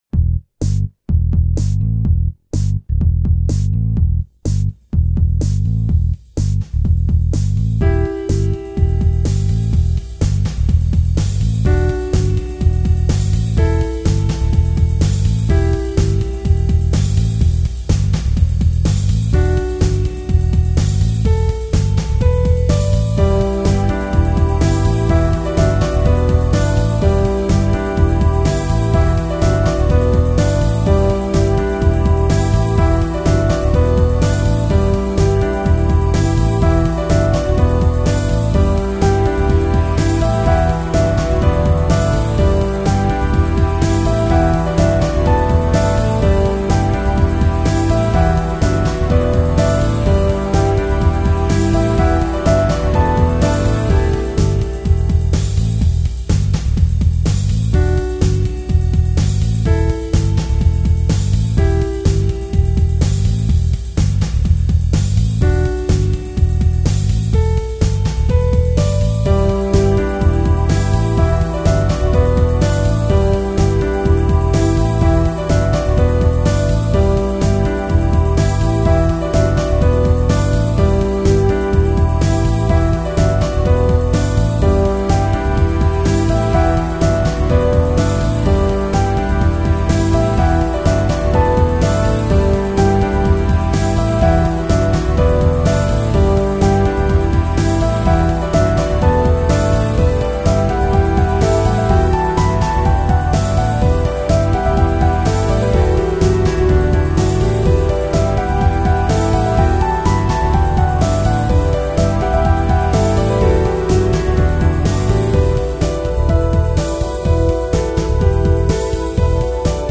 Melodic Tech